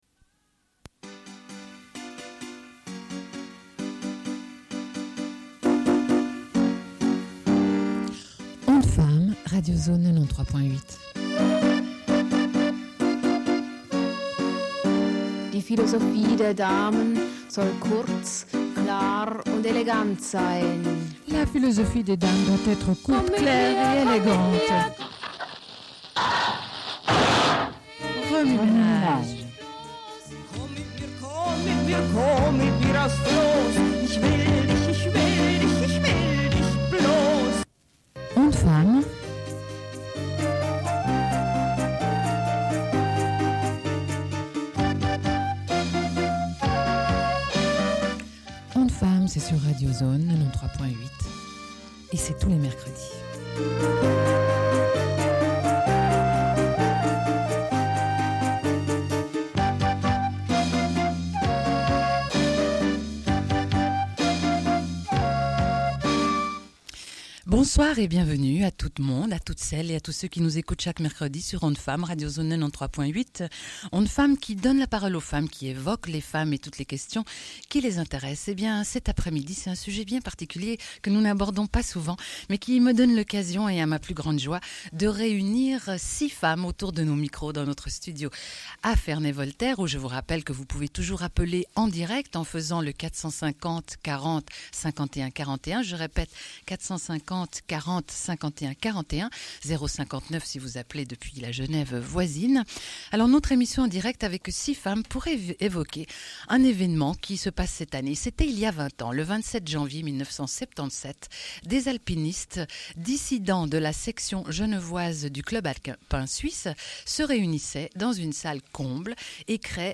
Sommaire de l'émission : à l'occasion des 20 ans du Groupe alpin genevois (GAG), avec six femmes en direct.